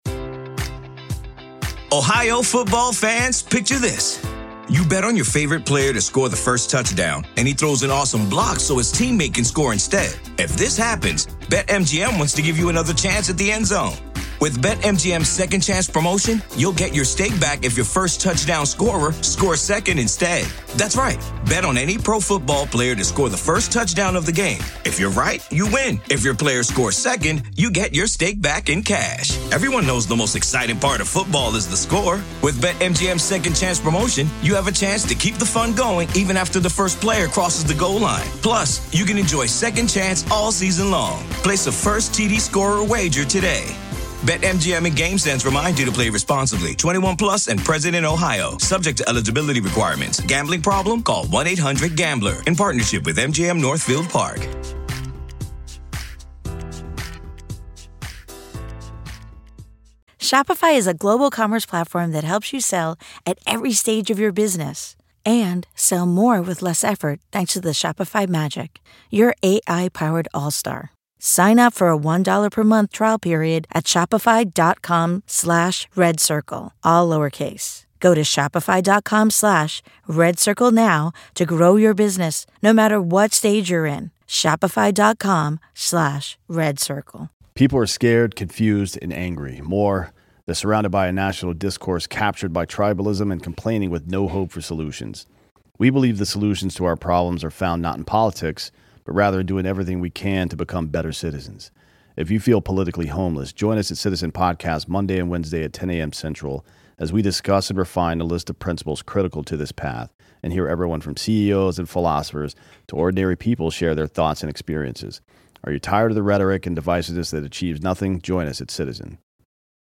M, his kids, and the Rebels are hunted by Safe Society, law enforcement, and the mob of indoctrinated, zombie-like civilians. CONTENT WARNINGS Moderate language, moments of unsettling, intense, shrill, and sudden loud sound design and unsettling voices.